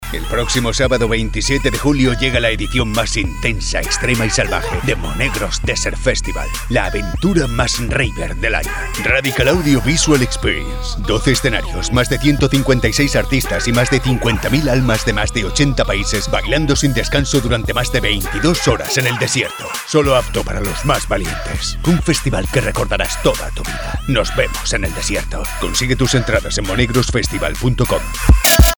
British Radio, TV Commercial Voice Overs Talent
Never any Artificial Voices used, unlike other sites. All our voice actors are premium seasoned professionals.
Adult (30-50) | Older Sound (50+)